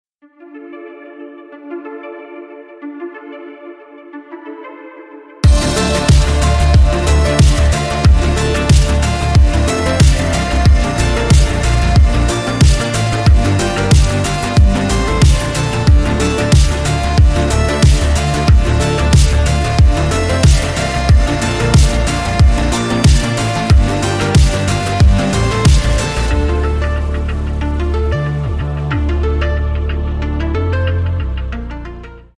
Рингтоны Без Слов
Рингтоны Электроника